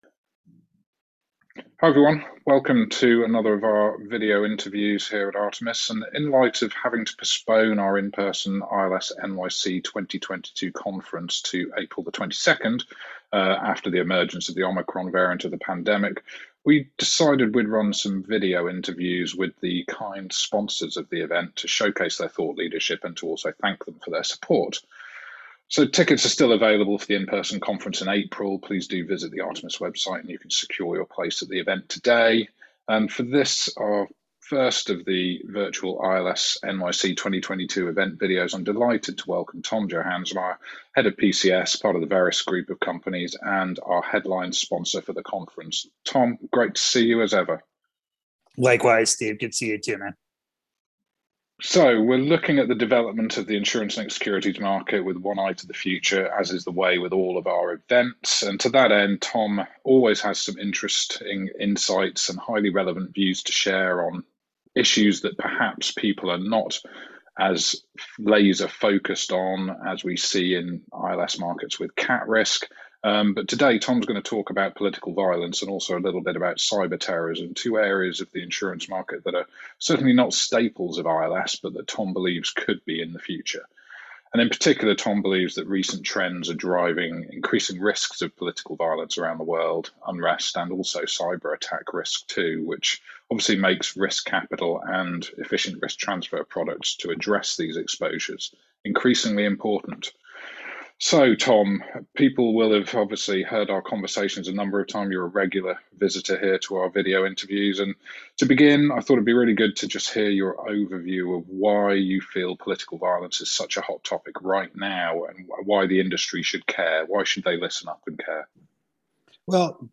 Artemis ILS NYC 2022 interview